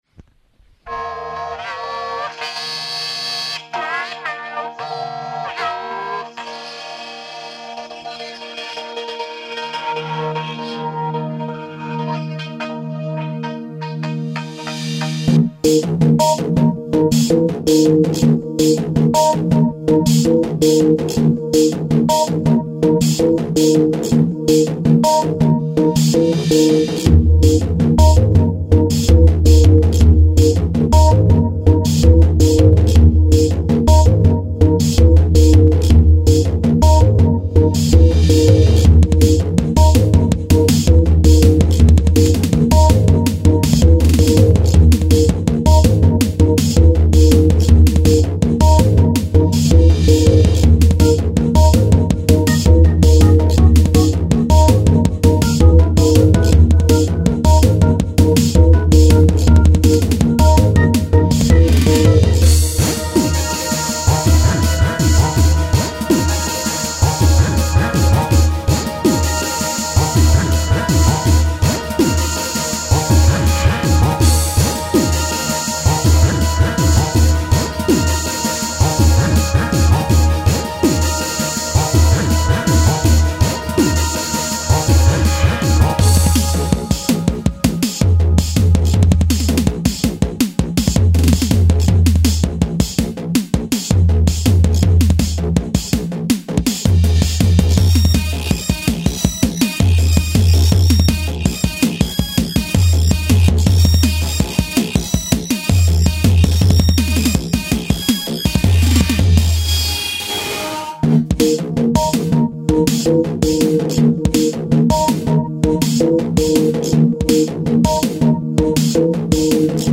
File under: Cheap Electronica